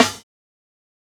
TC2 Snare 27.wav